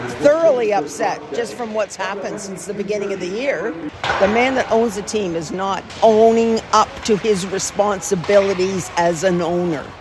This fan also described shared their frustration with ownership and coaching instability.